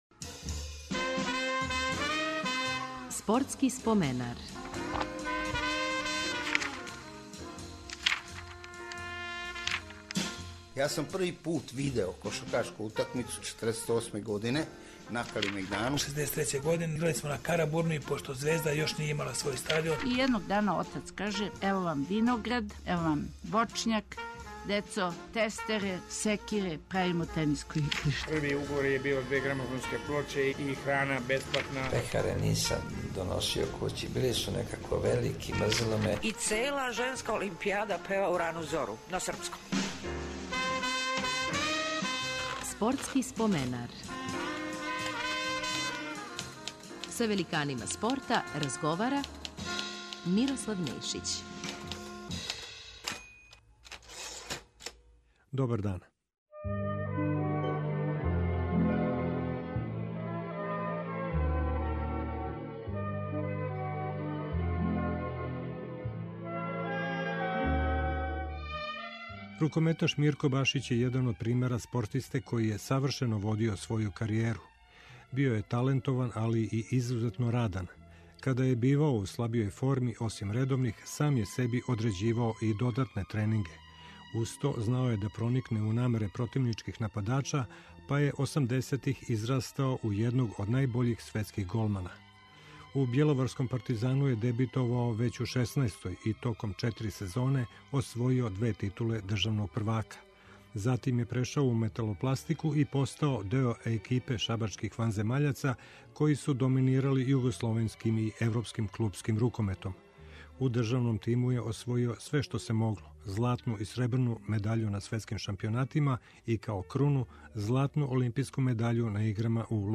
Гост 246. емисије биће рукометаш Мирко Башић.